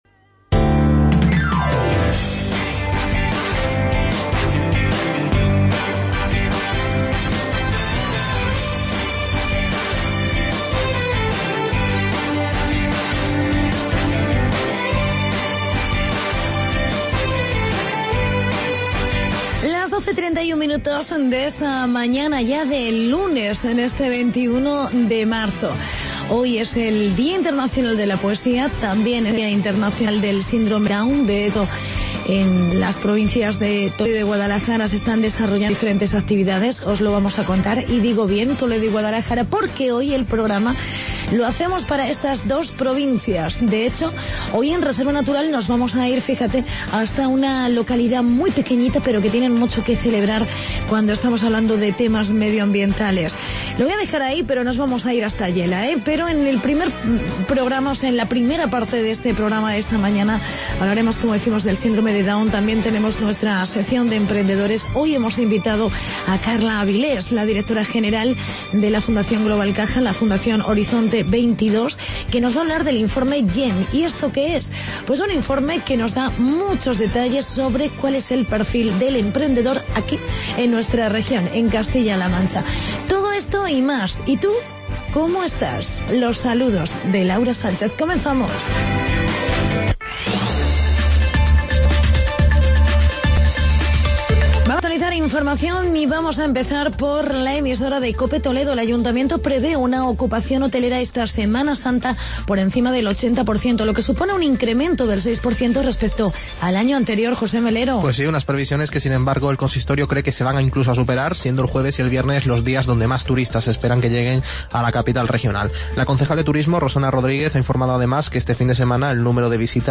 Reportaje sobre el Día Internacional del Síndrome de Down